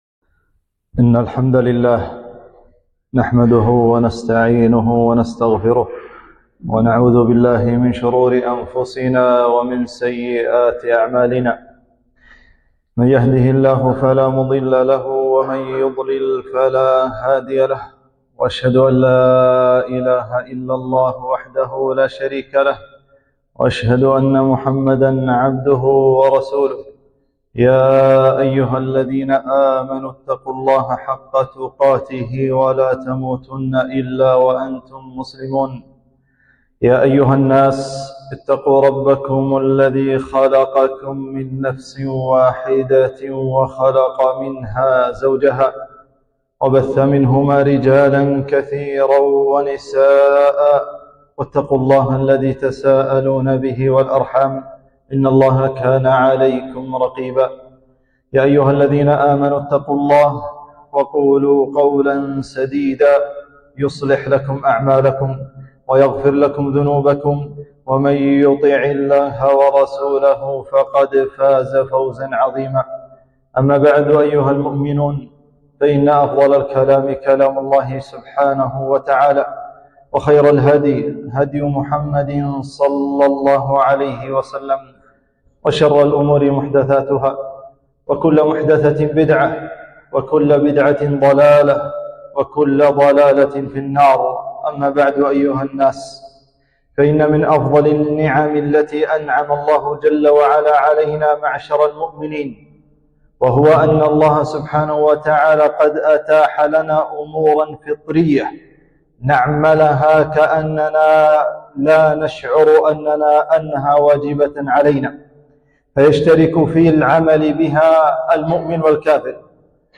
خطبة فضل النفقة على الأهل والأولاد